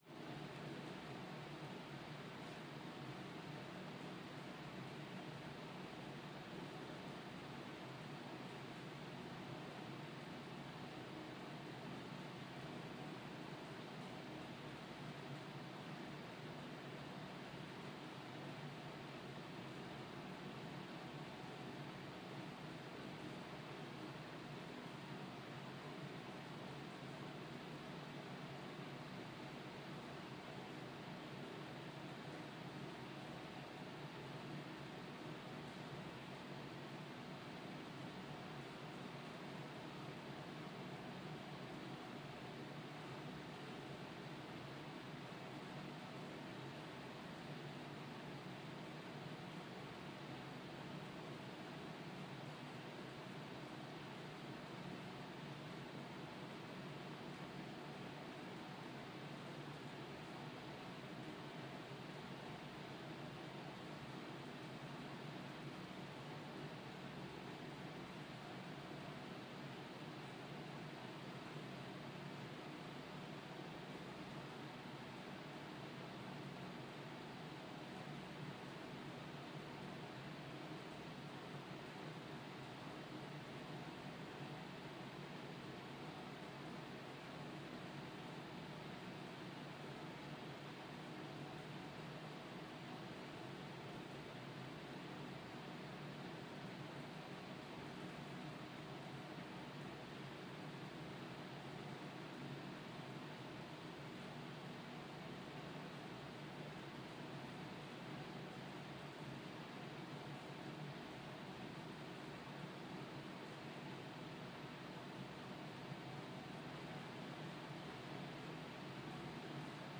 Звуки комнаты
Звук пустого пространства в тишине одиночества